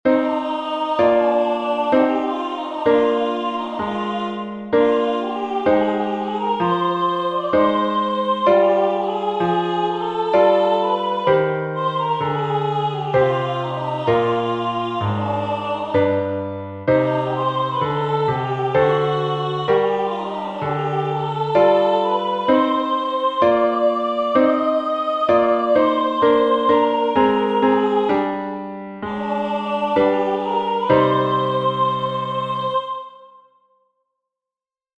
Entoación con acompañamento
Melodía e acompañamento: